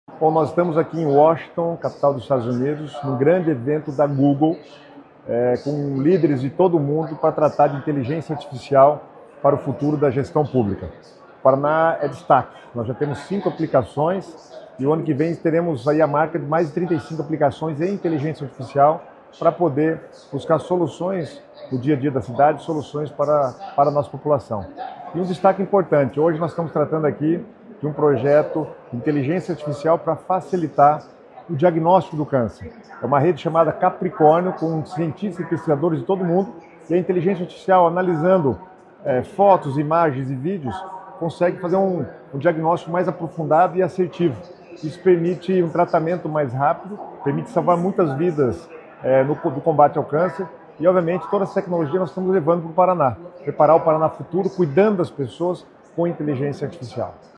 Sonora do secretário das Cidades, Guto Silva, sobre a participação do Estado no Google Public Sector Summit